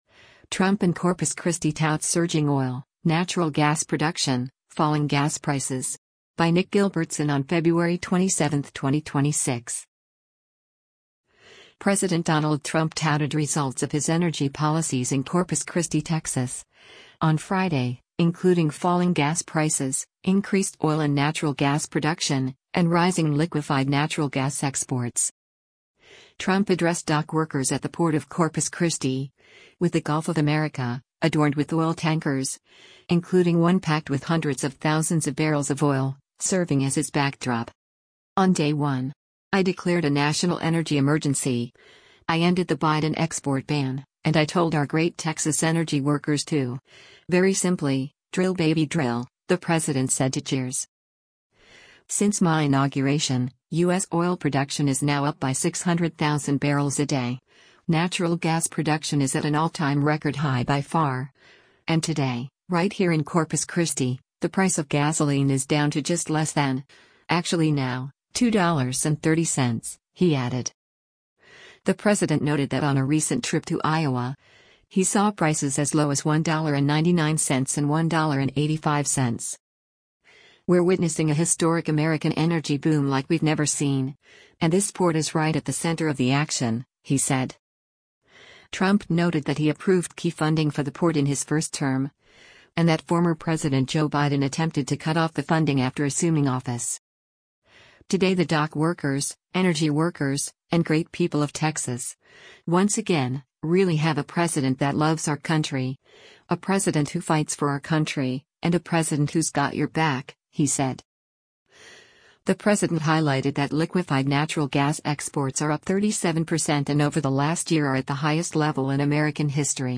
Trump addressed dock workers at the Port of Corpus Christi, with the Gulf of America, adorned with oil tankers, including one packed with hundreds of thousands of barrels of oil, serving as his backdrop.
“On day one. I declared a national energy emergency, I ended the Biden export ban, and I told our great Texas energy workers to, very simply, drill baby drill,” the president said to cheers.